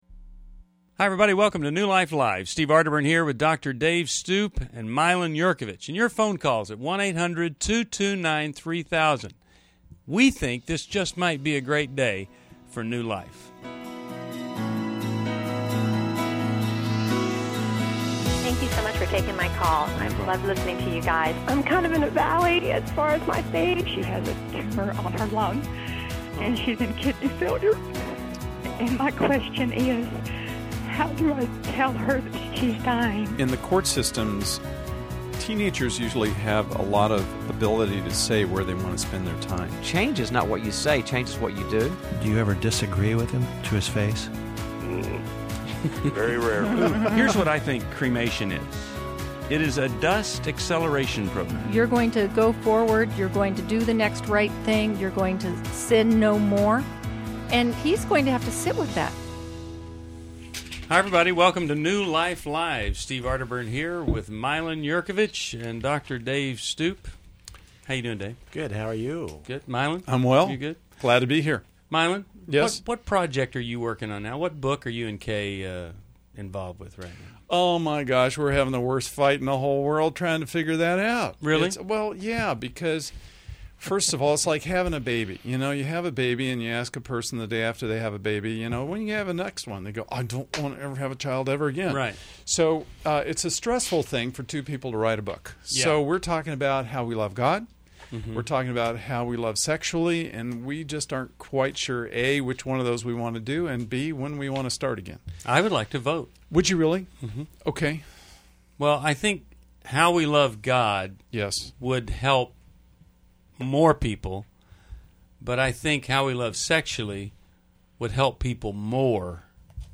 New Life Live: July 8, 2011 addresses divorce, emotional abuse, and forgiveness. Callers seek guidance on healing, faith, and blended family challenges.